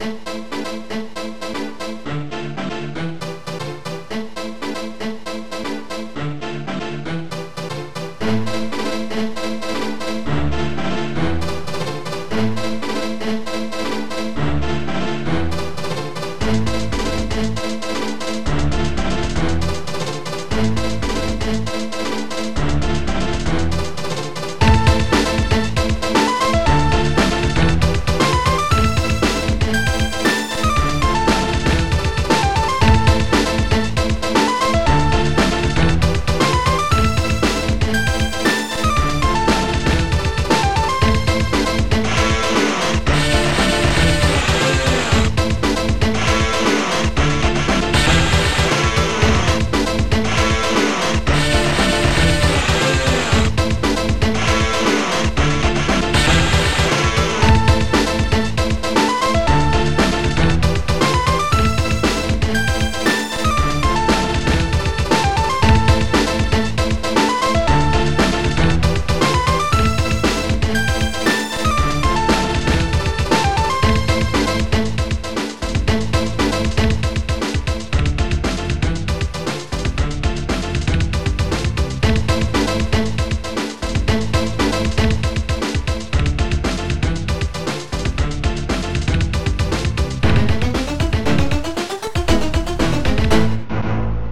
BassDrum13
SnareDrum
HiHAT1
DragonBass
PianoStrings
GuitarMadnes